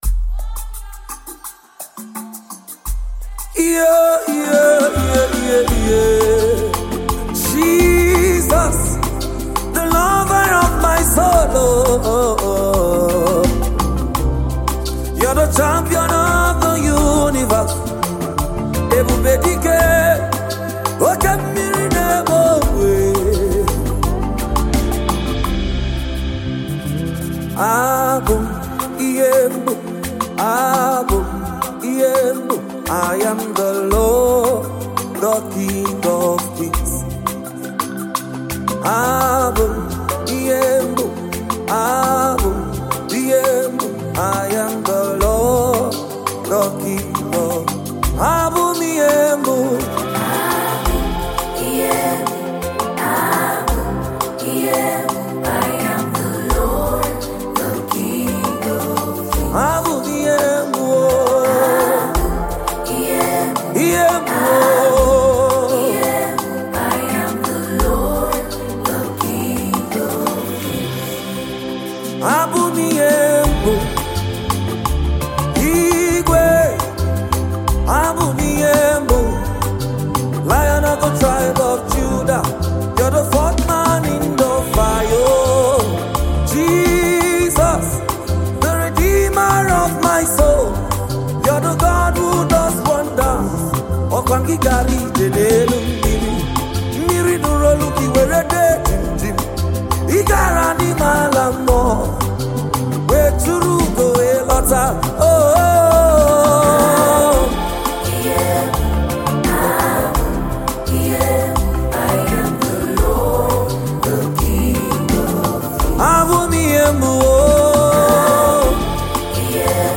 He a prophetic praise and worship minister